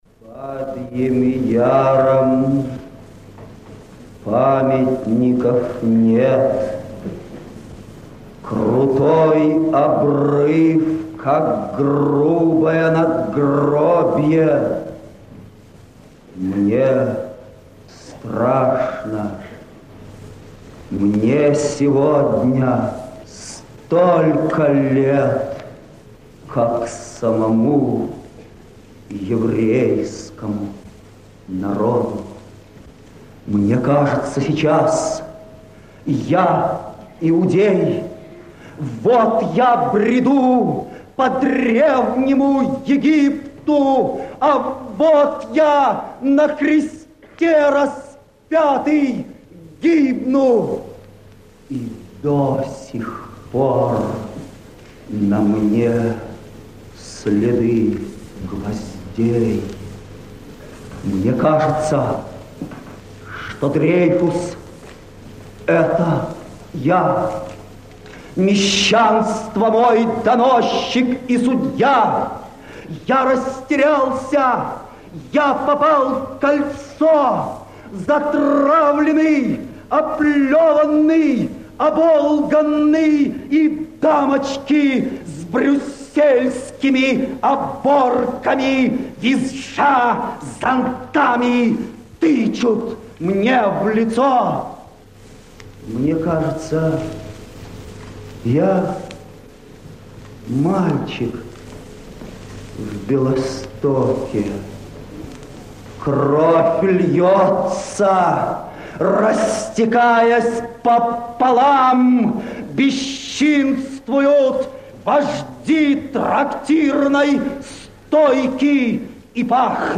Yevtushenko Reads 'Babi Yar' (In Russian)